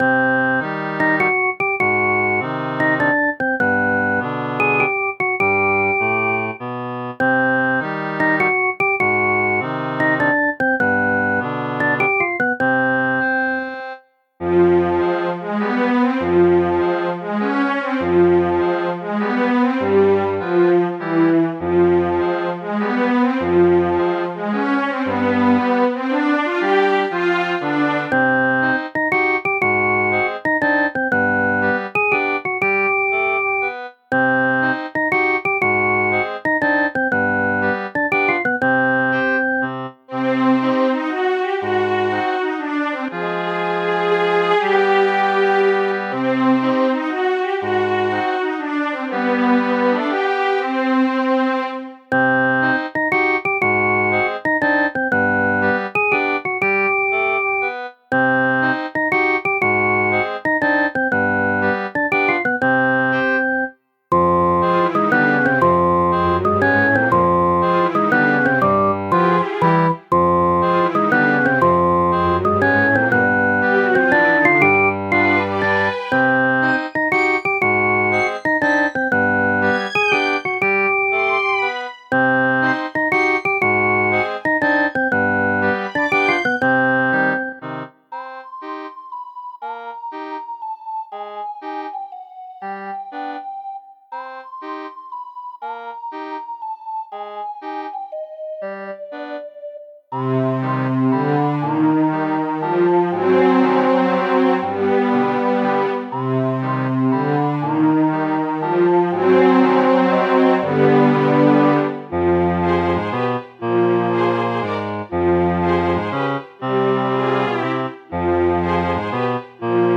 オルガンで奏でられる高貴で怪しく、魅力的なメロディ
ゆったり、怪しい暗い、オルガン